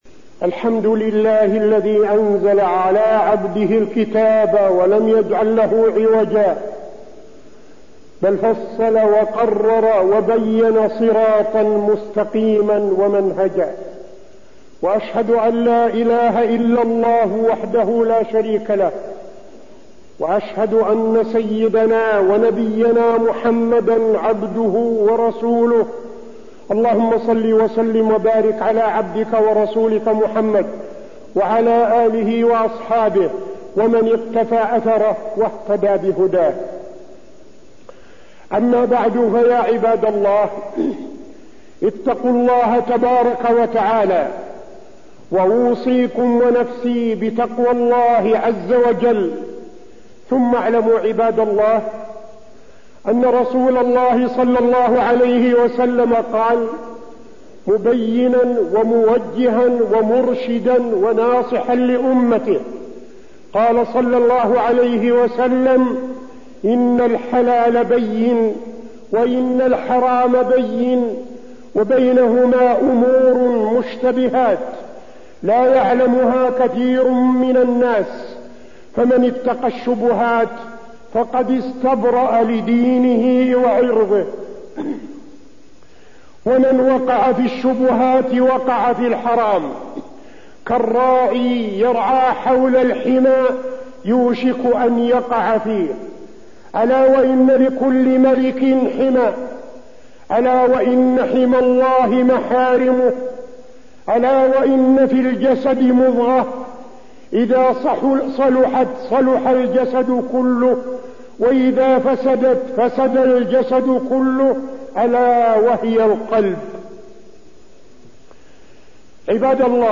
تاريخ النشر ١٧ ربيع الثاني ١٤٠٤ هـ المكان: المسجد النبوي الشيخ: فضيلة الشيخ عبدالعزيز بن صالح فضيلة الشيخ عبدالعزيز بن صالح الحلال والحرام والربا